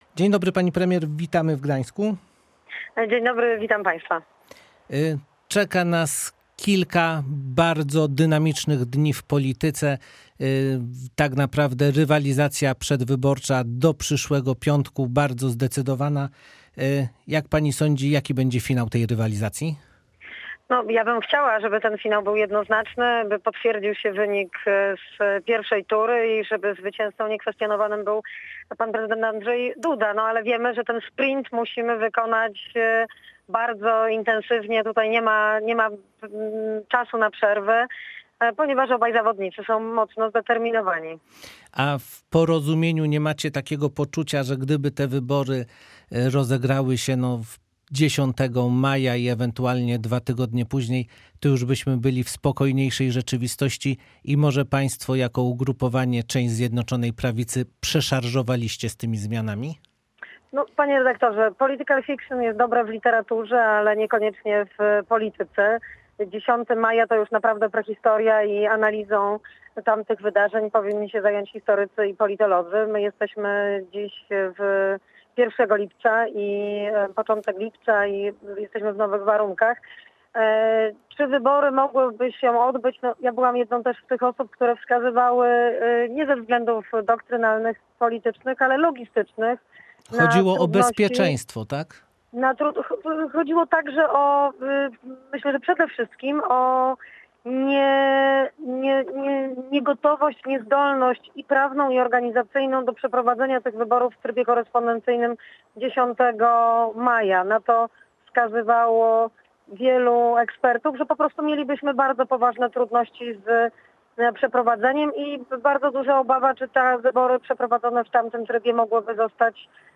rozmawiał z Jadwigą Emilewicz, wicepremier, minister rozwoju i wiceprezes Rady Ministrów.